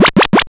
wipwipwip.wav